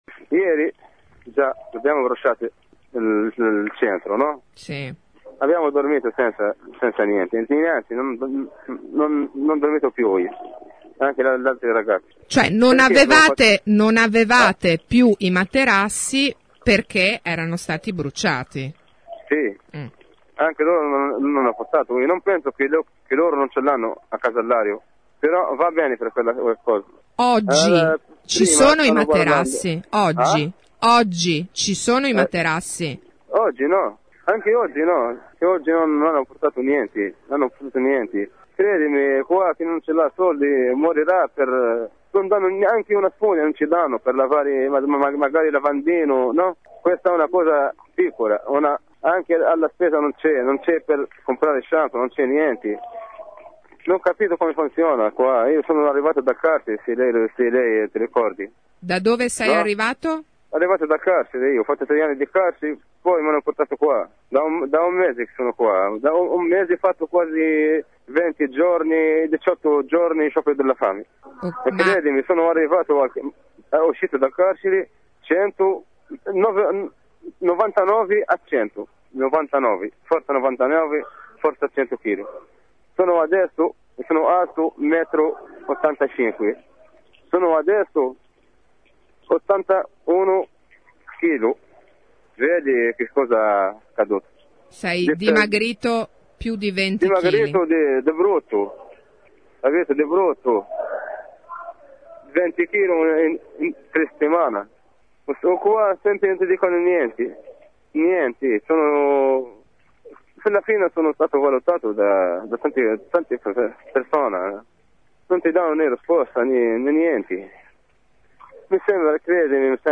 è una voce che sembra aver esaurito ogni speranza.